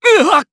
Siegfried-Vox_Damage3_jp.wav